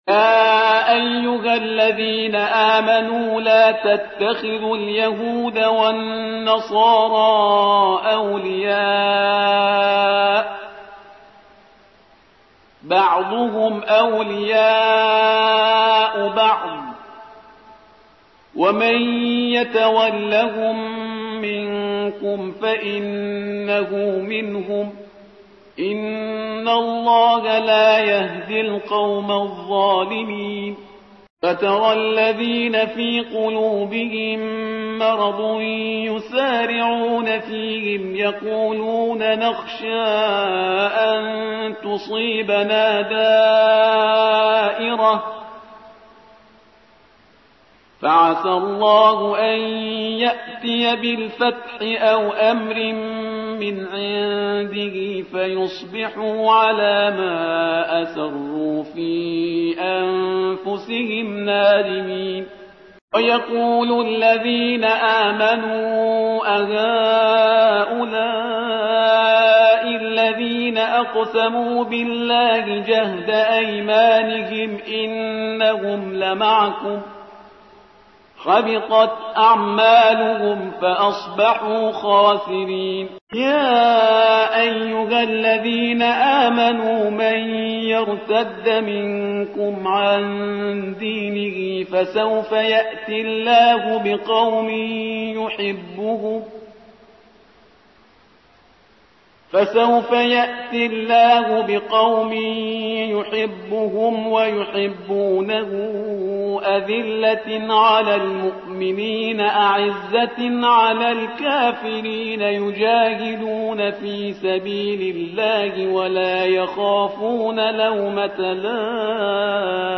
ترتیل صفحه ۱۱۷ سوره سوره مائده با قرائت استاد پرهیزگار(جزء ششم)